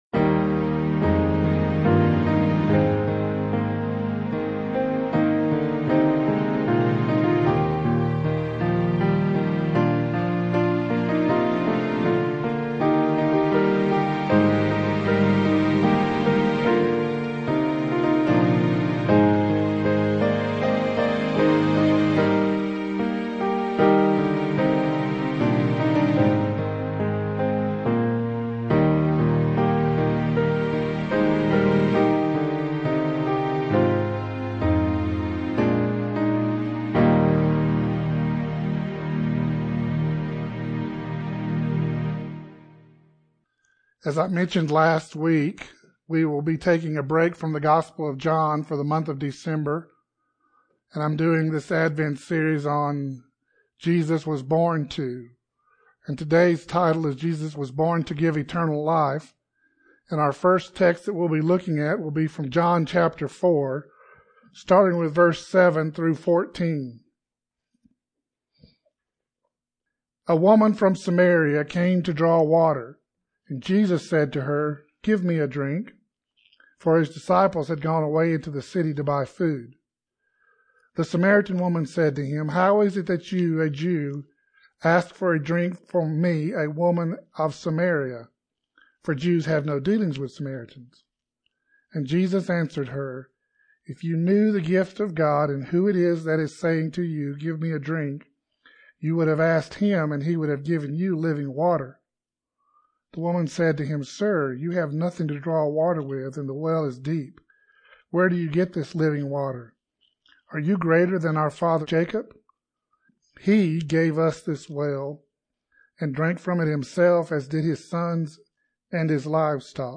1 Sermon - He Was Born To Give Eternal Life 34:31